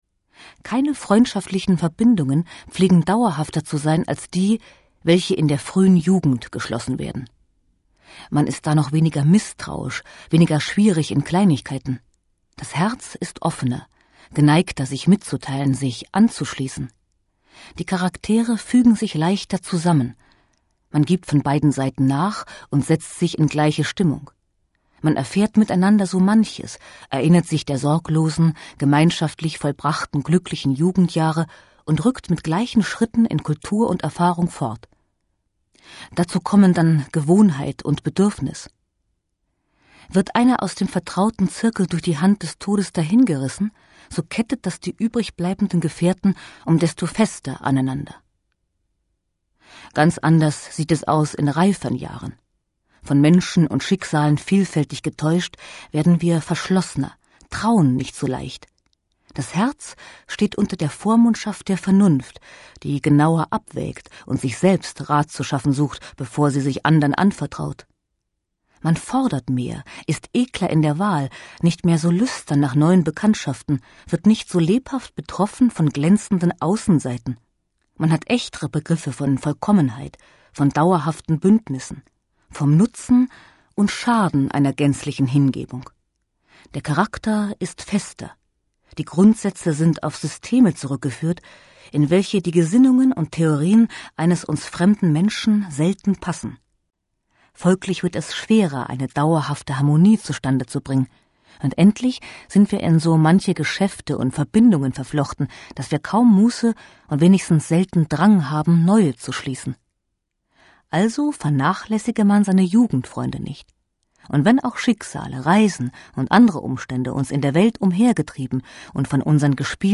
Adolph Freiherr von Knigge Sprecherin